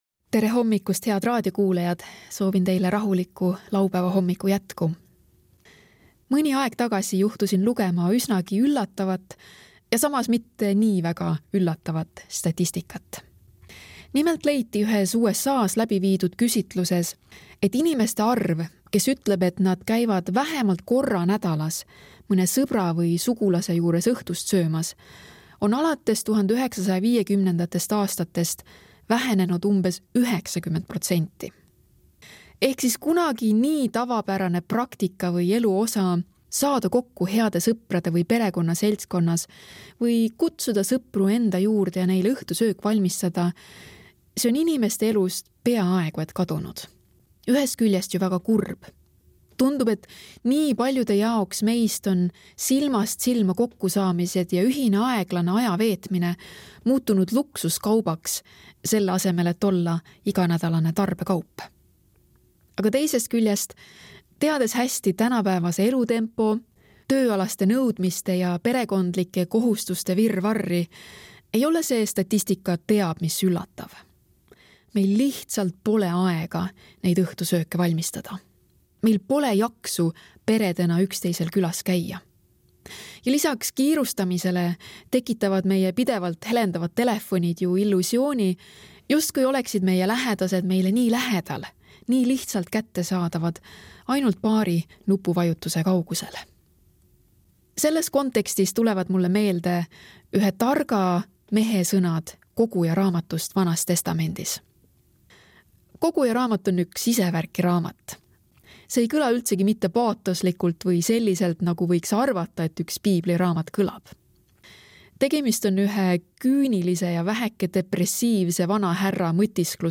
Hommikupalvused